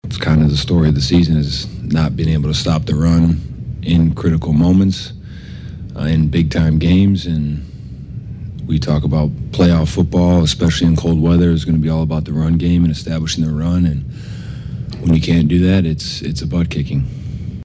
A disgusted TJ Watt says the defense has to be better than that.
nws0584-tj-watt-got-our-butts-kicked.mp3